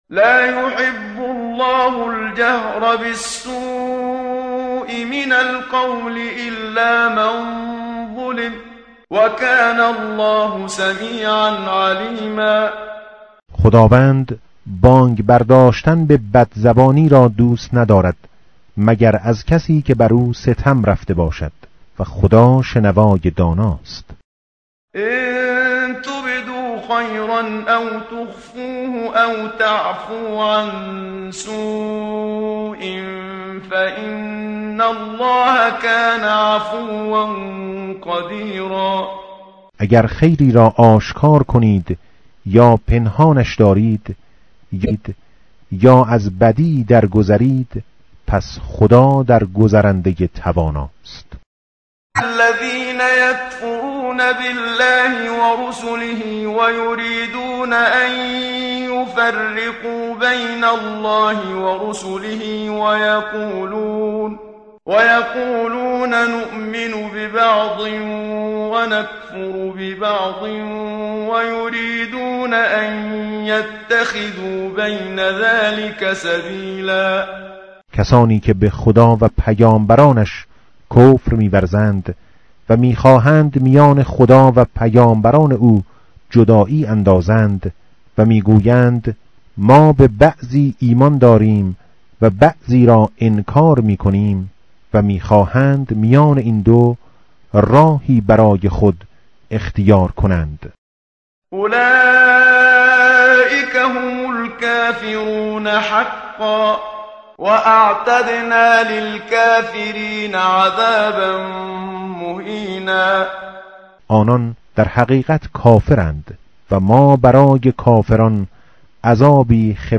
tartil_menshavi va tarjome_Page_102.mp3